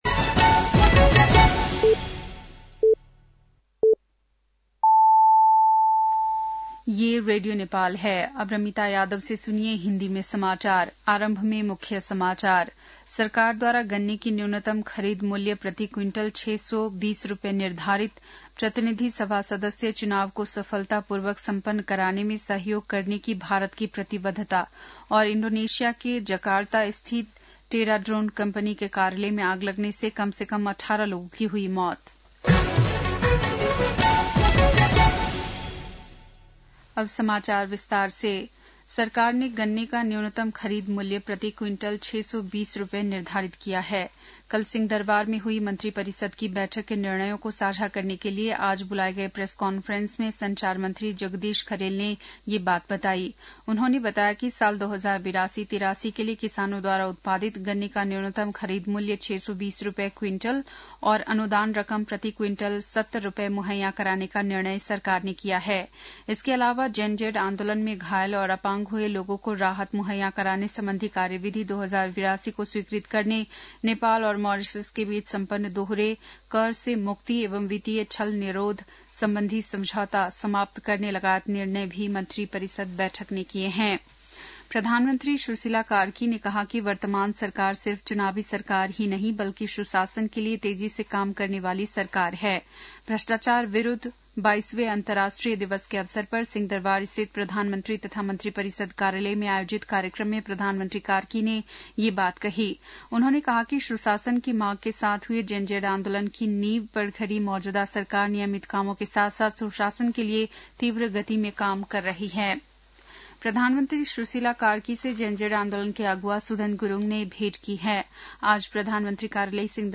बेलुकी १० बजेको हिन्दी समाचार : २३ मंसिर , २०८२
10-pm-hindi-news-8-23.mp3